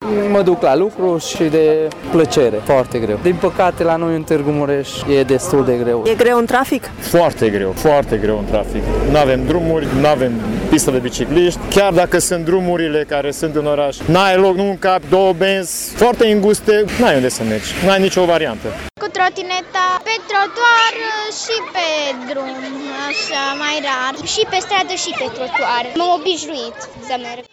La rândul lor, conducătorii de trotinete din Târgu Mureș se plâng că nu au pe unde să se deplaseze, iar conducătorii de autovehicule nu îi respectă în trafic.